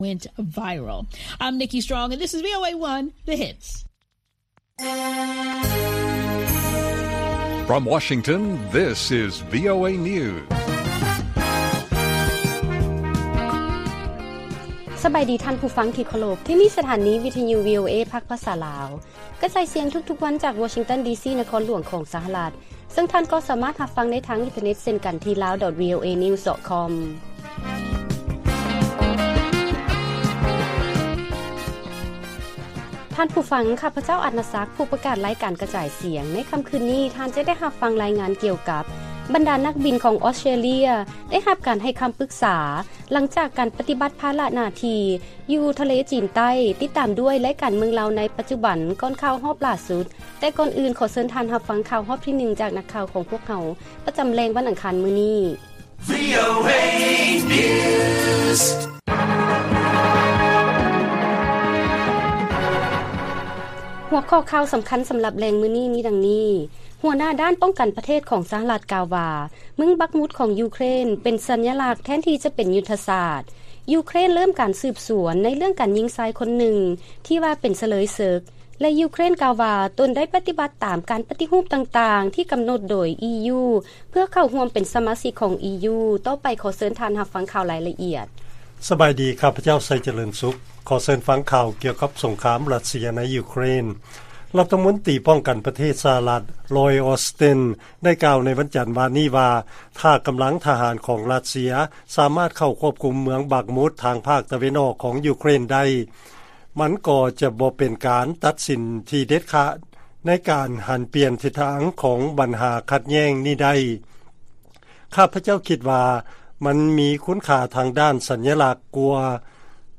ວີໂອເອພາກພາສາລາວ ກະຈາຍສຽງທຸກໆວັນ, ຫົວຂໍ້ຂ່າວສໍາຄັນໃນມື້ນີ້ມີ: 1. ເມືອງບັກມຸດ ຂອງຢູເຄຣນ ເປັນສັນຍາລັກ ແທນທີ່ຈະເປັນຍຸດທະສາດ, 2. ຢູເຄຣນ ເລີ້ມການສືບສວນ ການຍິງຜູ້ຊາຍຄົນນຶ່ງ, ແລະ 3. ຢູເຄຣນ ກ່າວວ່າ ຕົນໄດ້ປະຕິບັດຕາມ ການປະຕິຮູບຕ່າງໆທີ່ກຳນົດໂດຍ ອີຢູ ເພື່ອເຂົ້າຮ່ວມເປັນສະມາຊິກຂອງອີຢູ.